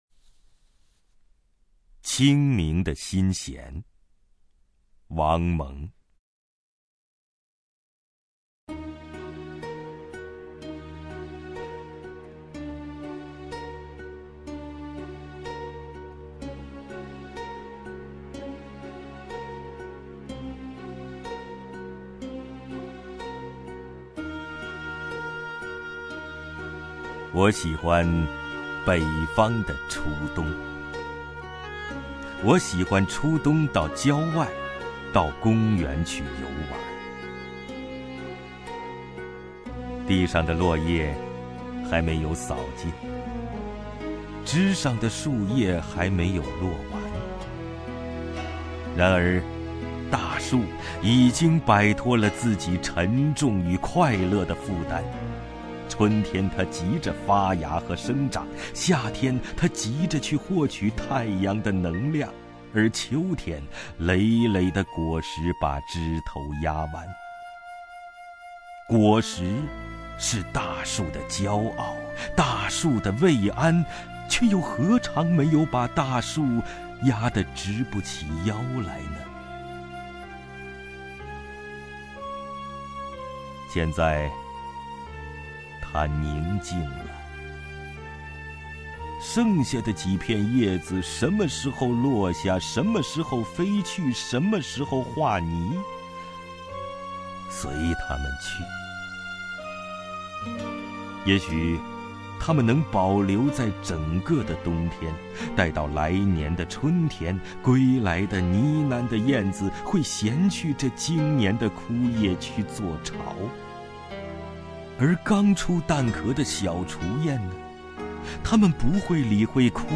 首页 视听 名家朗诵欣赏 王凯
王凯朗诵：《清明的心弦》(王蒙)　/ 王蒙